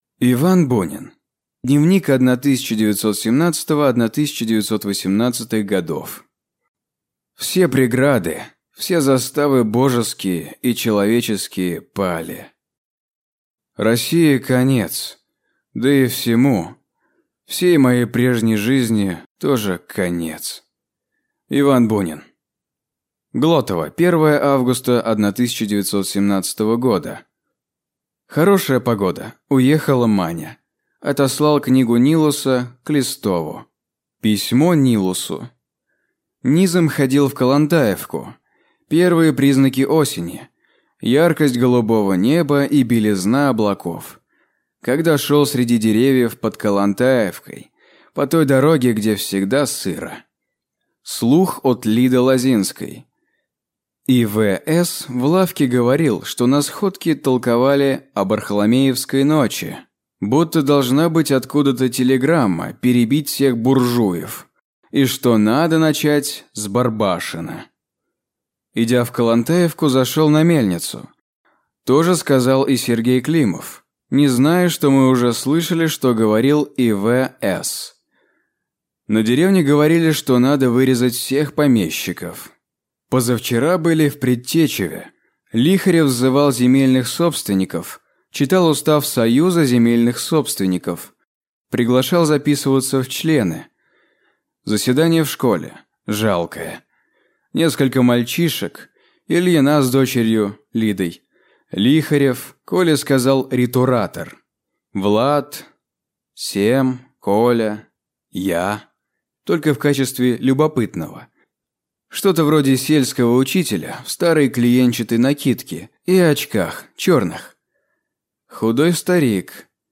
Аудиокнига Дневник 1917–1918 гг.